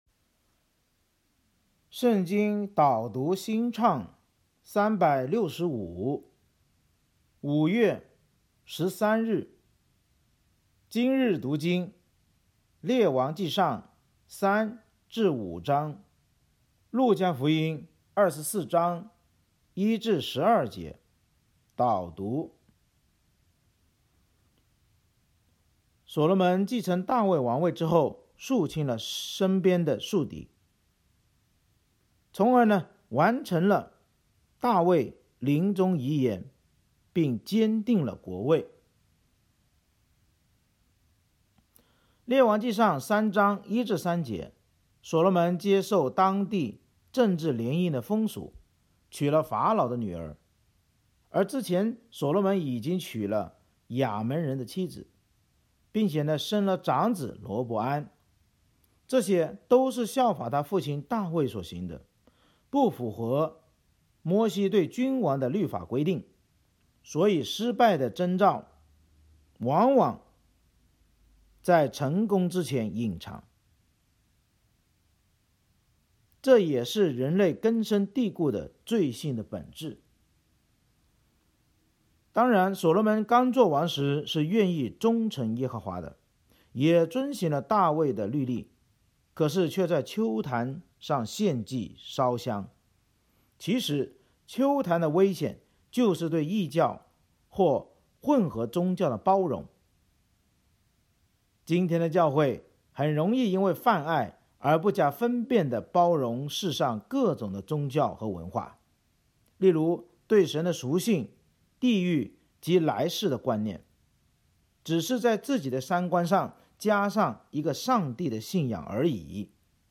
【经文朗读】